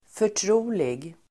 Uttal: [för_tr'o:lig]